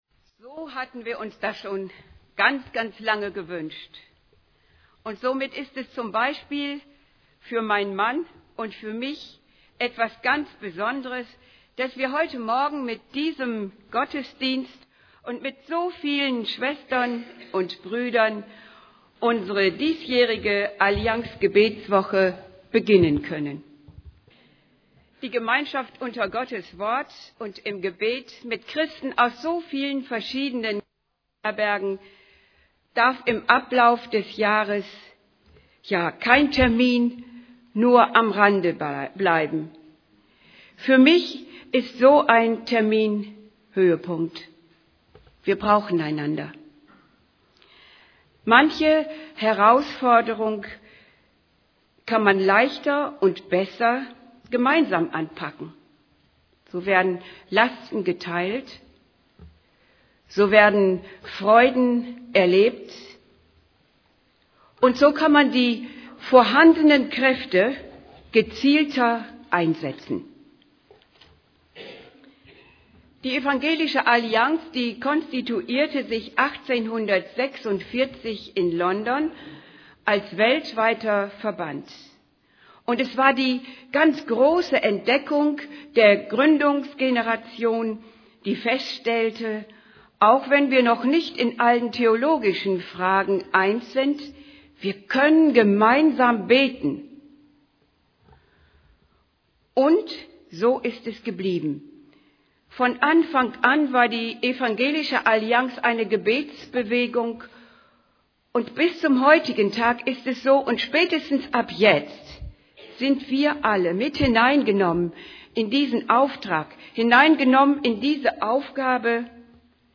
Gottesdienst zum Auftakt der Allianz-Gebetswoche